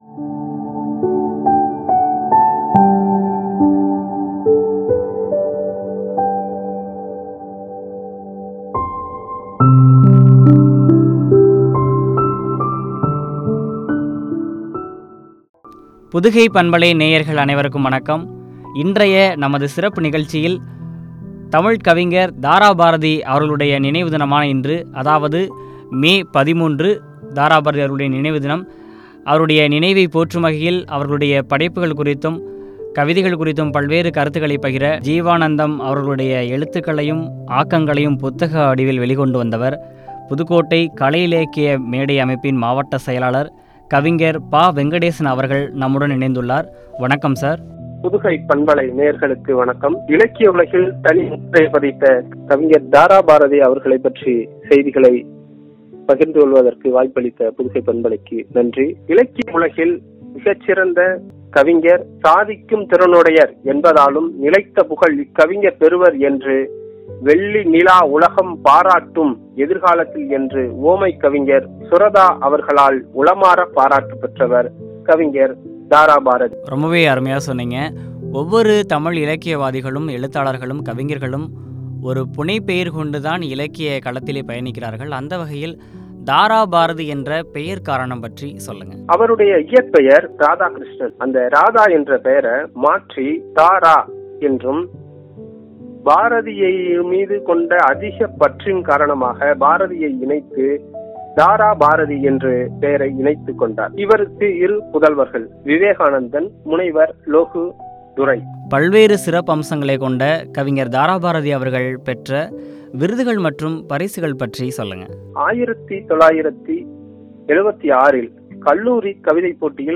இலக்கிய தொண்டும் பற்றிய உரையாடல்.